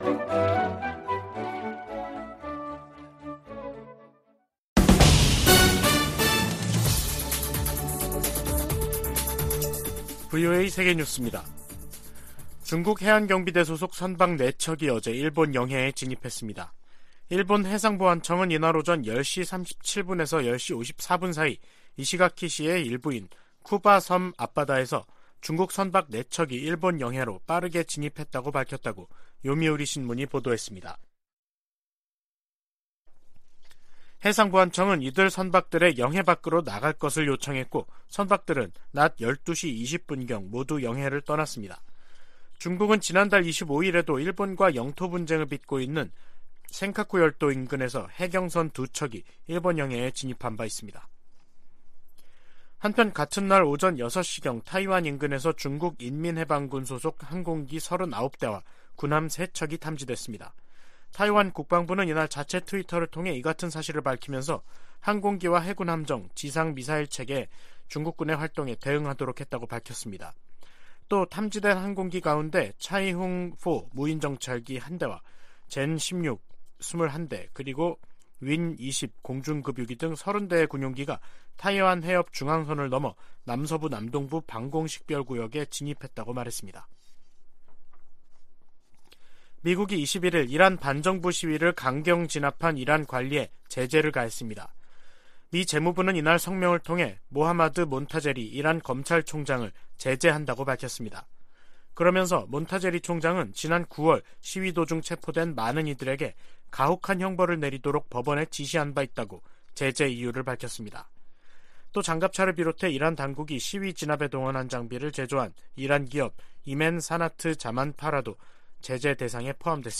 VOA 한국어 간판 뉴스 프로그램 '뉴스 투데이', 2022년 12월 22일 3부 방송입니다. 미국 의회는 2023회계연도 일괄 지출안에서 북한 관련 지출은 인권 증진과 대북 방송 활동에만 국한하도록 규정했습니다. 미국은 내년 아시아태평양경제협력체(APEC) 의장국으로서 경제적 지도력을 보여주고 역내의 경제 회복력 강화를 도모할 것이라고 밝혔습니다.